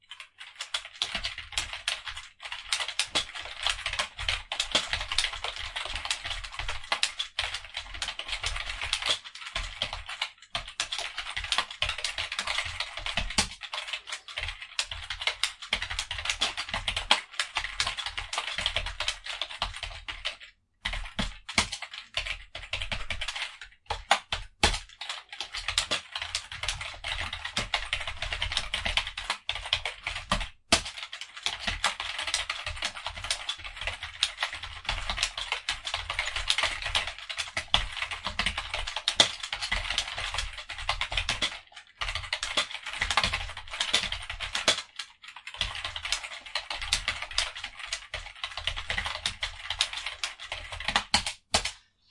键盘快速打字
描述：在电脑键盘上打字，每分钟约400500个字符。
Tag: 打字机按键 打字 电脑 键盘 办公室